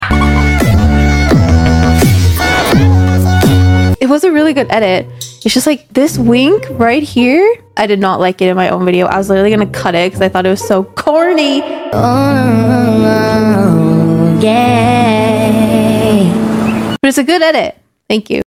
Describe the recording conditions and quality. btw sorry for the bad quality🙄